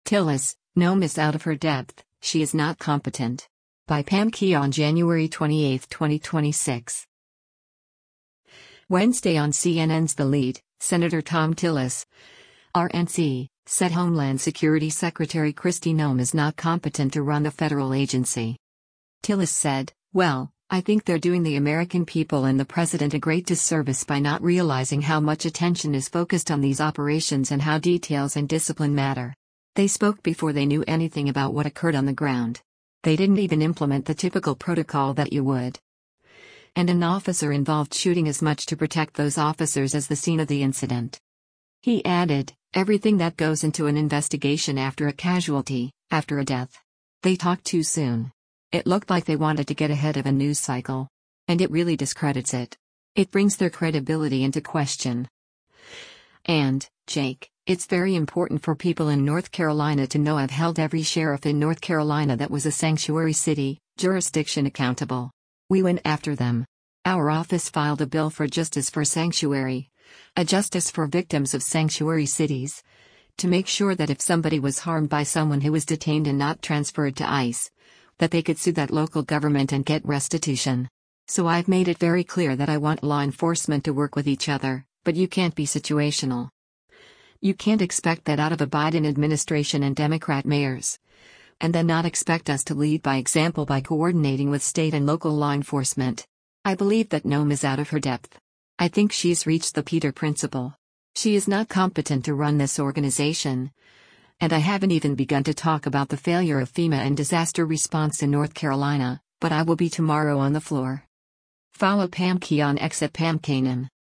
Wednesday on CNN’s “The Lead,” Sen. Thom Tillis (R-NC) said Homeland Security Secretary Kristi Noem “is not competent” to run the federal agency.